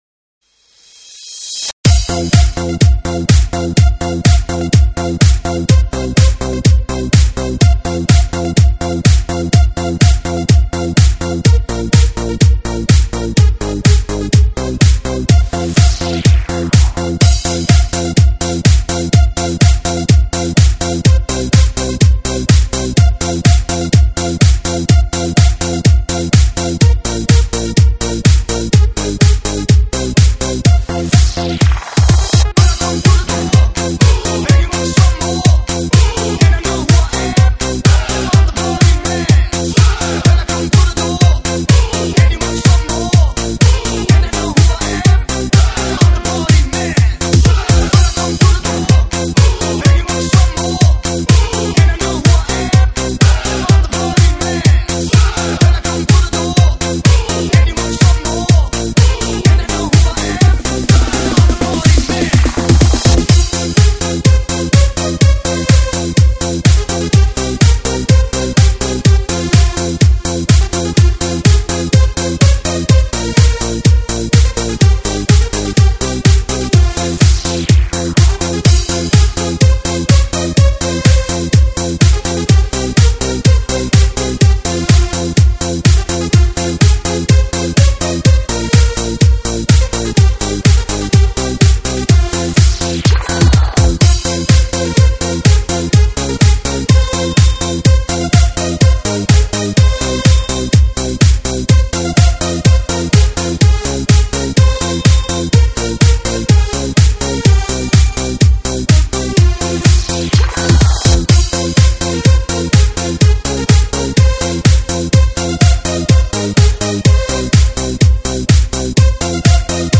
音质： 320 Kbps